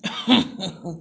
cough_2.wav